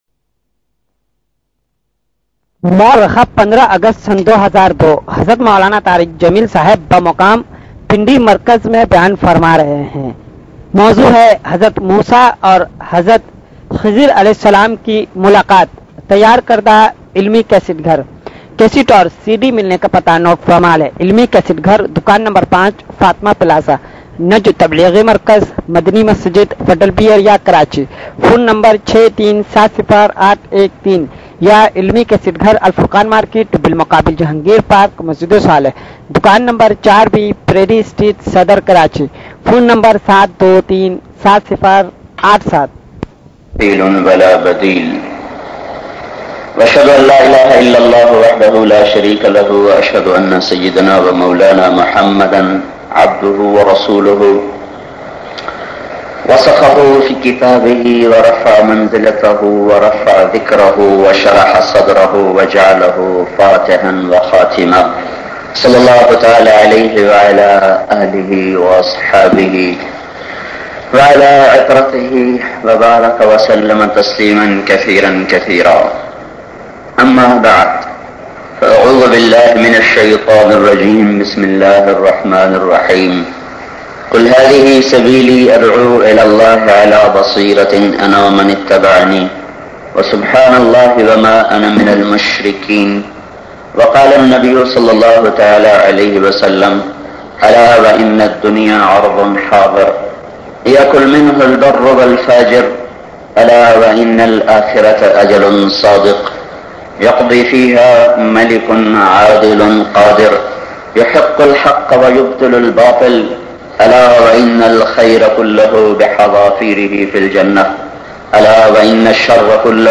WAQIYA KIZAR bayan MP3